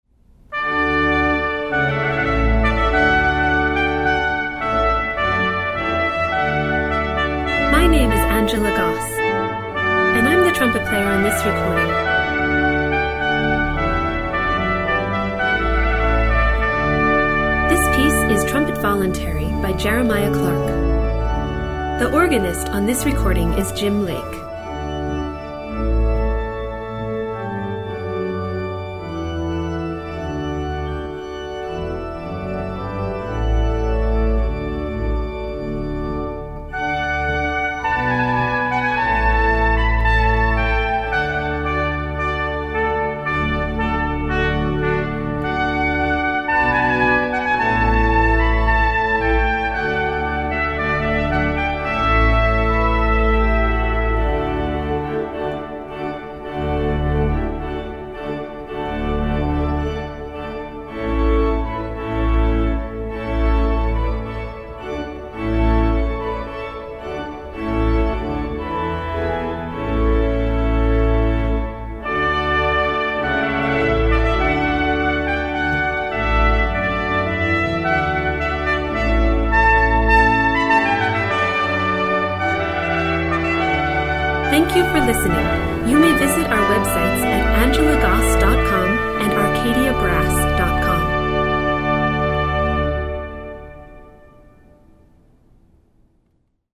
For a simple ceremony, Arcadia Brass can provide one of our less expensive ensembles, such as a trumpet duo.
stately procession (mp3) for your entrance, and play